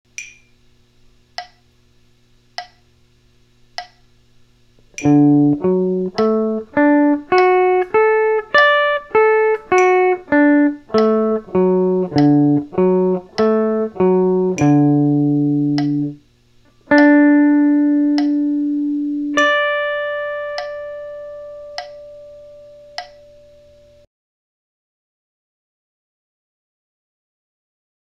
The G Major Form Arpeggio
Here is the G form arpeggio using the CAGED guitar method:
caged-g-form-arp.m4a